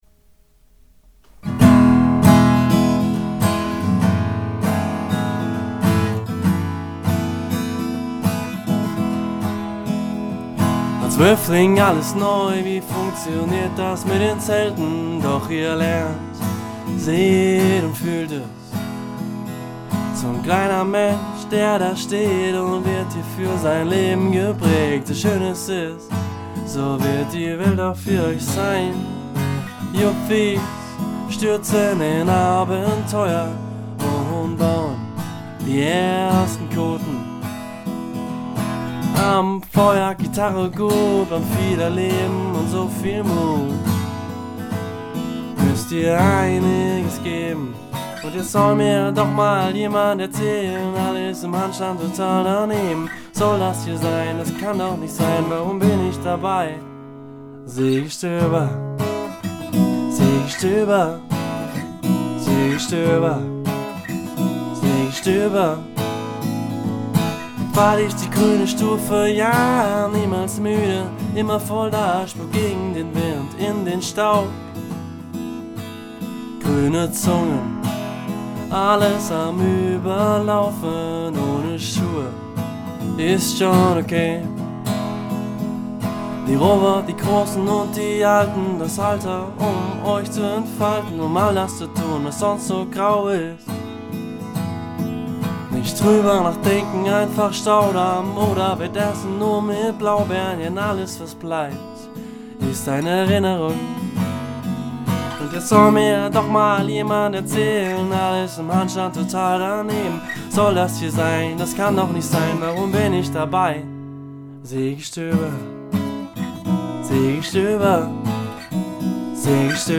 Lagersong „Seegestöber“
Lagersong_01.mp3